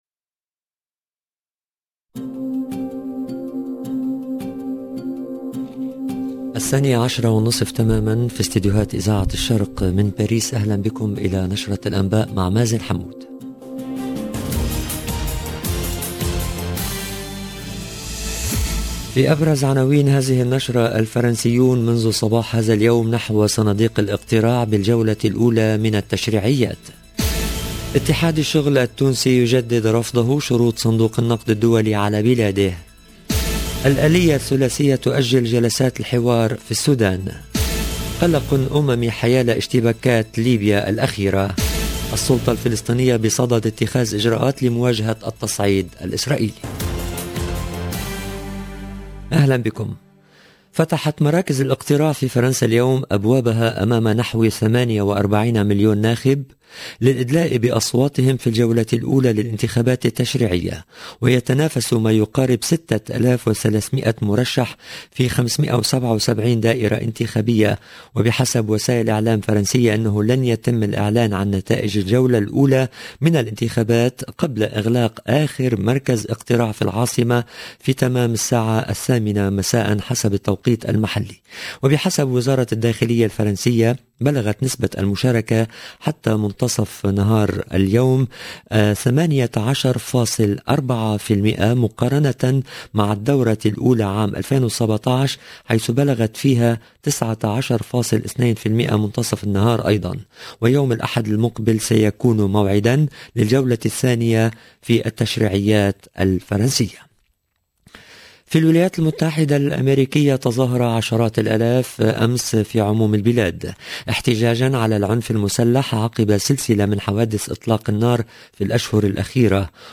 LE JOURNAL DE MIDI 30 EN LANGUE ARABE DU 12/06/22